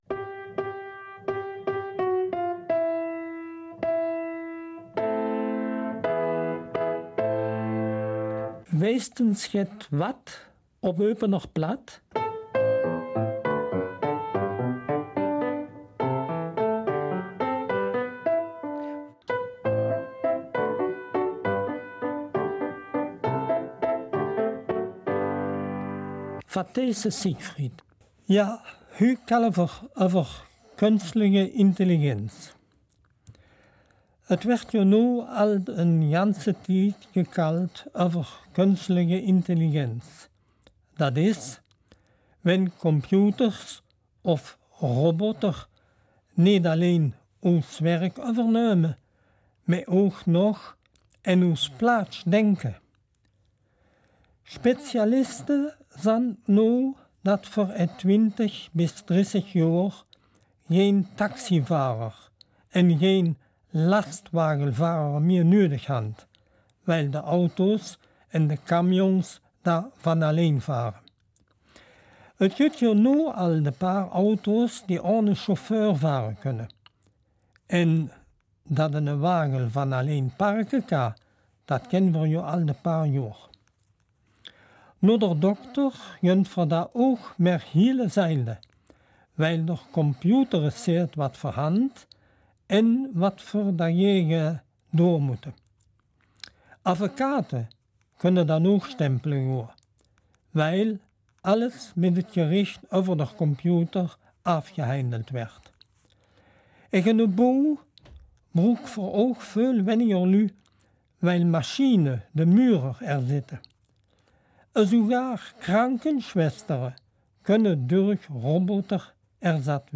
Eupener Mundartsendung